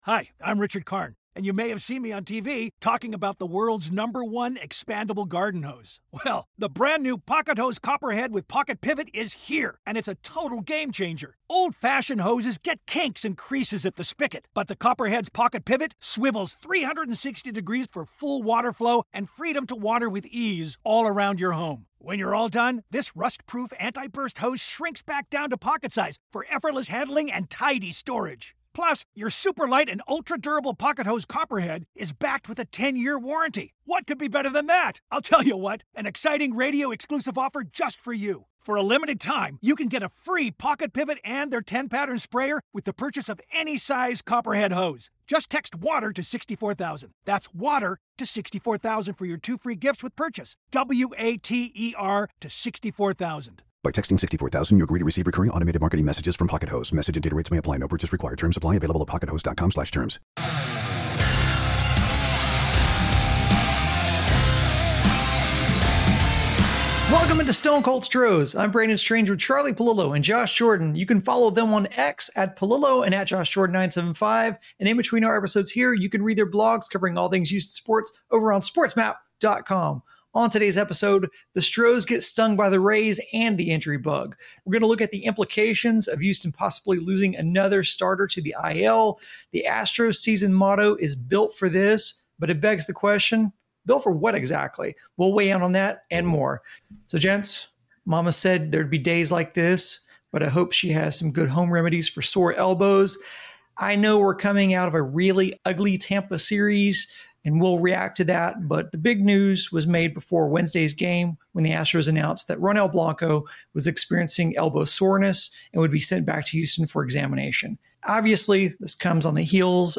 Can Houston tread water with 3/5ths of their rotation on the shelf? With another setback to the pitching, the Stone Cold Stros panel takes a hard look at the curious roster construction that really begs the ques...